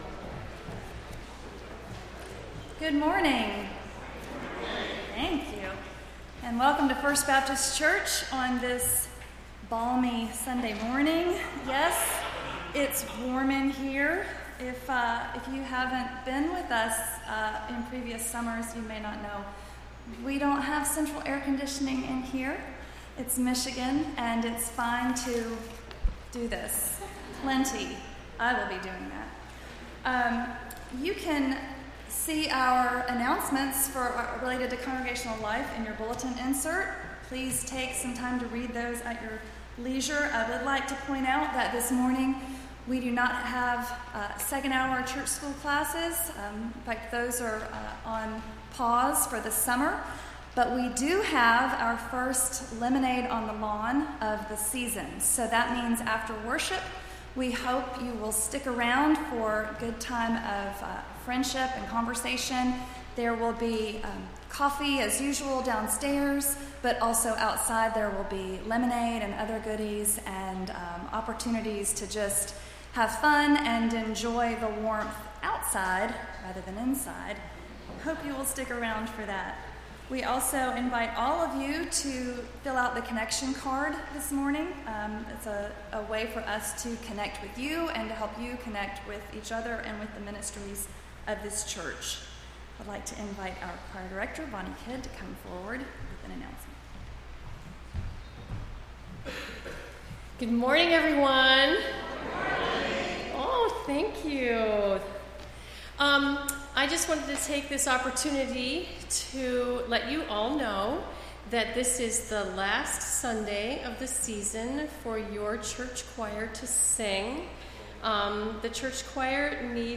Entire June 17th Service